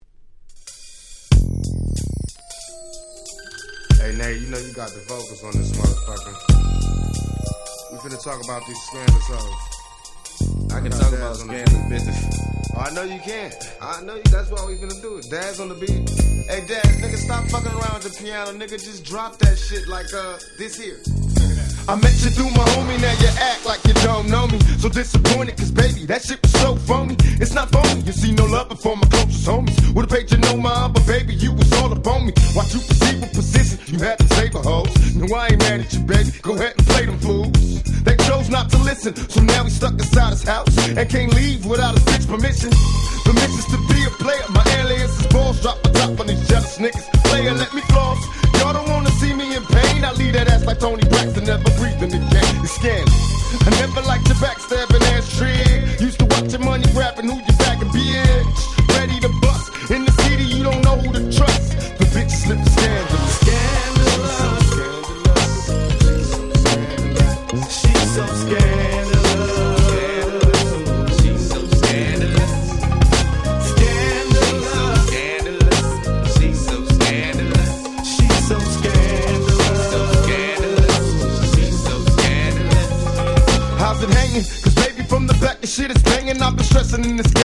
96' Smash Hit West Coast Hip Hop !!
G-Rap Gangsta Rap